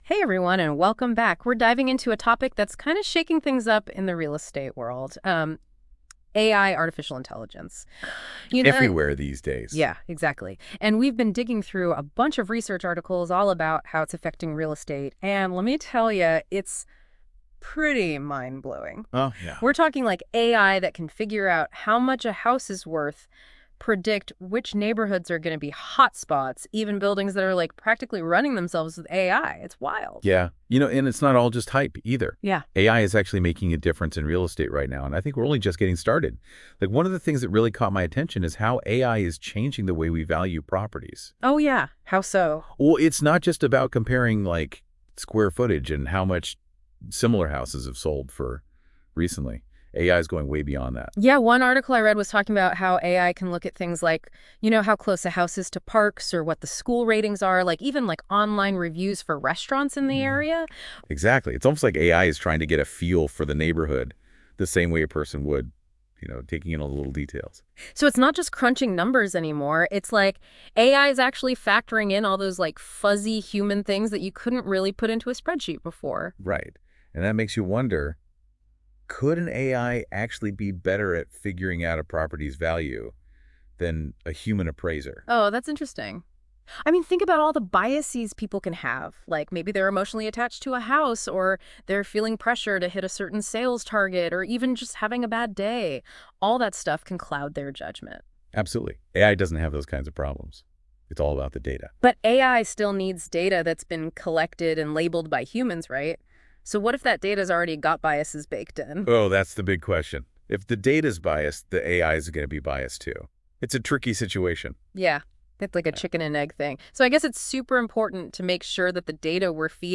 This article was generated using an AI tool to explore the profound impact of artificial intelligence on the real estate industry. We took a second step after the article was generated to leverage another AI-powered tool to transform this article into a dynamic podcast interview, illustrating how AI can seamlessly shift between content formats.
Please read the article below and then listen to the podcast which was created by feeding the AI Generated article into a second Generative AI tool (Google Notebook LM).